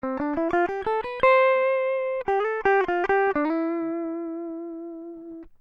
한편(F) Front.mp3는 그림 02의 근처를, 각도를 주지 않고 약하게 연주하고 있습니다. 같은 프레이즈에서도 마일드한 재즈풍으로 들리지 않습니까?
이 2개의 소리는 같은 기타로 세팅도 완전히 같은 상태로 녹음하고 있습니다.